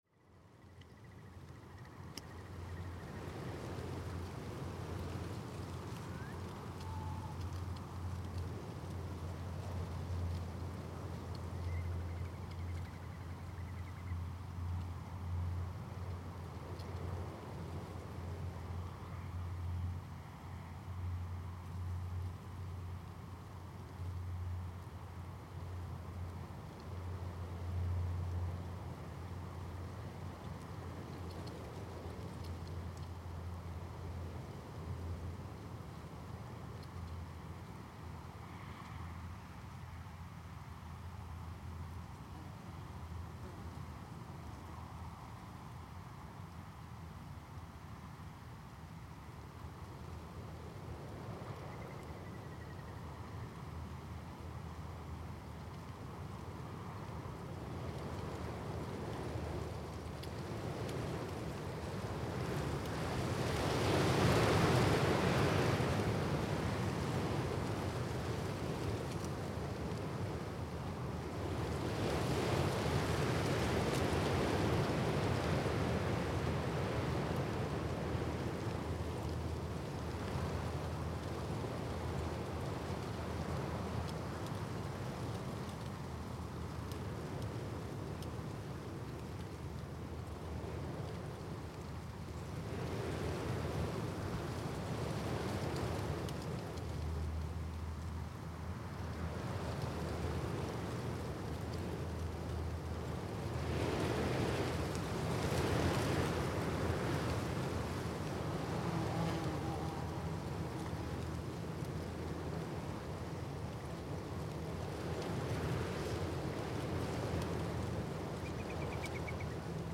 There is not much going on in this recording. Many decades have passed since farming was in the area and most birds are quiet this time of the day. So gust is playing the main role in the recording.
It was recorded on four channels with 50dB gain and HPF at 80Hz. Quality open headphones are recommended while listening at low to mid level, or in speakers at low level.